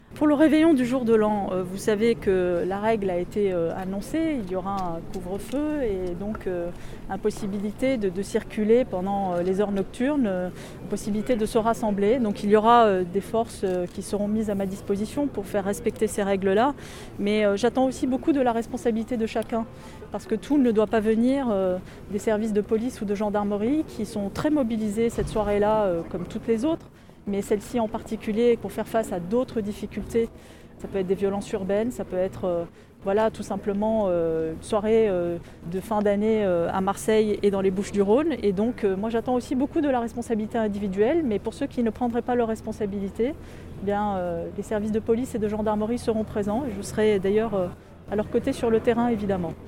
A l’issue d’une cérémonie de prise de fonction dans les formes, avec un dépôt de gerbe au monument des policiers morts du département des Bouches-du-Rhône, un passage en revue des troupes, discours… la nouvelle préfète de police des Bouches du Rhône a rencontré la presse.